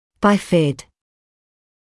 [‘baɪfɪd][‘байфид]расщепленный или разделенный на две части; двураздельный